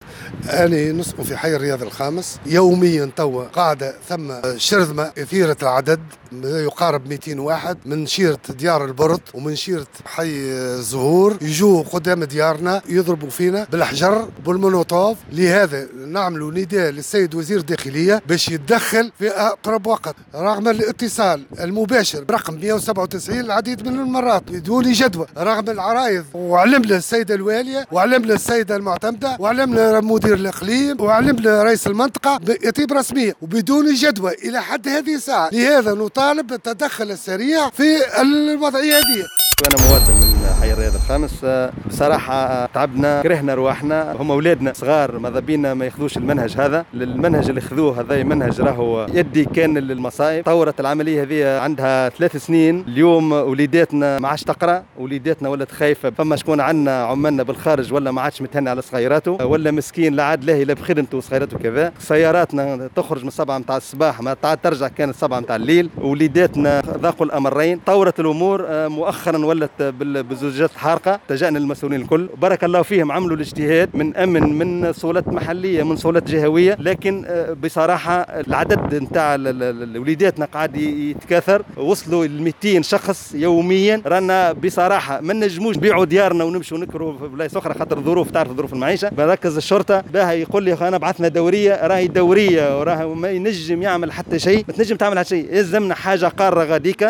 وناشد الأهالي في تصريح للجوهرة أف أم ، السلطات والوحدات الأمنية بالتدخّل ،مطالبين بتركيز وحدة أمنية قارّة خاصة وأن هذه الظاهرة موجودة منذ سنة 2018 وفق قولهم.
عدد من اهالي حي الرياض 5